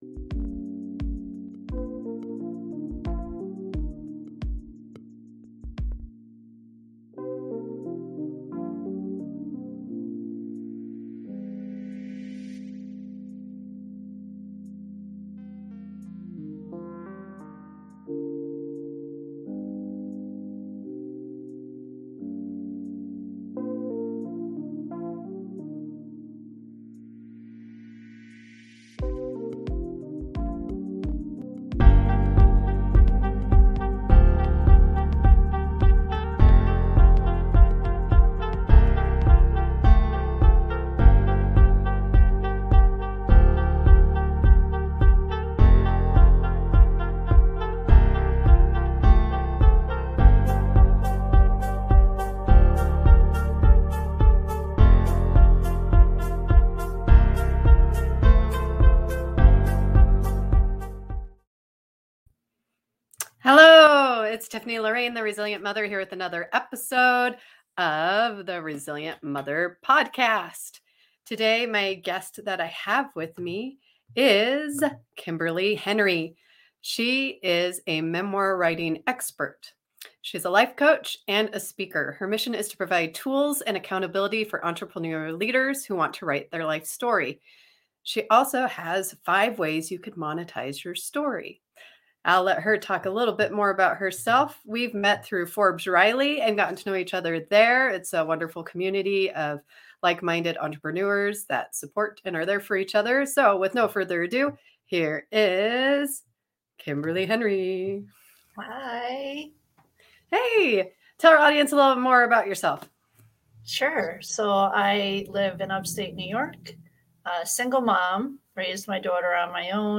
Here is a discussion on resistance vs resilience, how awareness then acceptance are the first steps to creating resilience in your life.